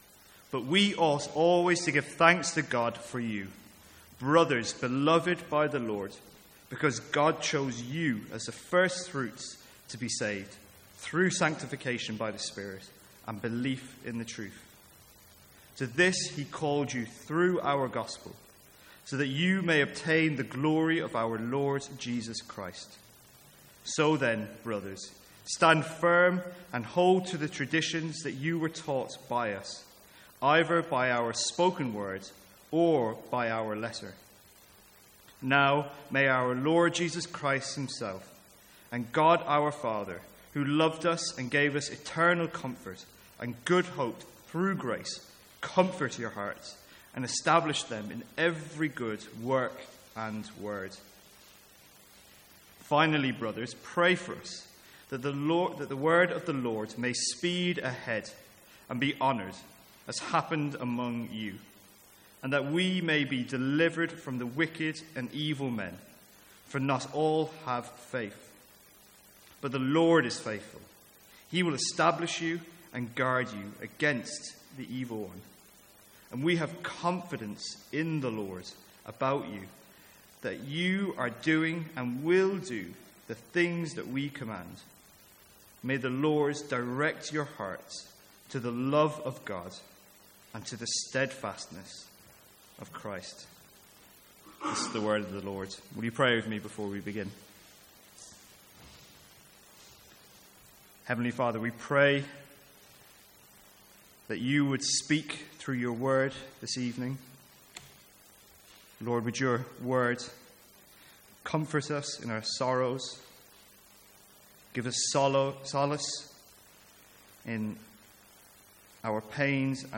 Sermons | St Andrews Free Church
From the Sunday evening series in 2 Thessalonians.